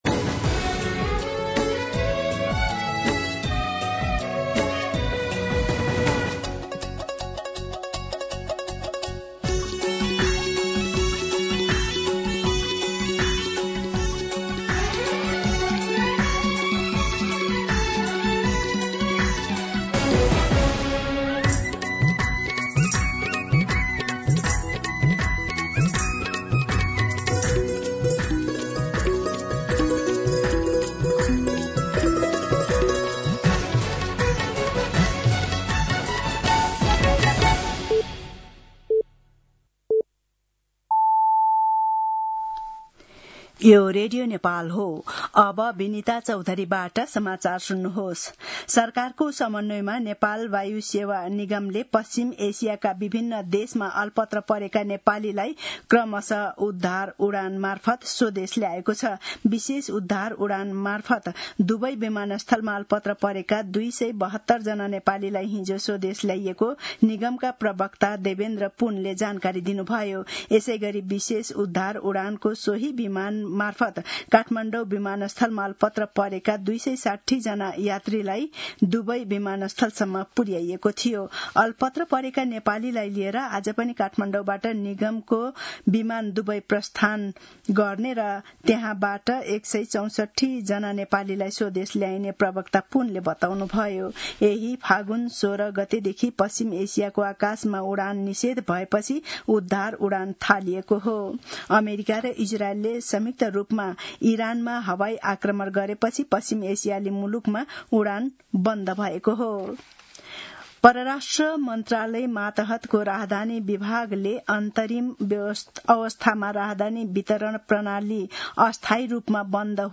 दिउँसो १ बजेको नेपाली समाचार : २९ फागुन , २०८२